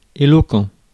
Ääntäminen
US : IPA : [ˈspiːkɪŋ]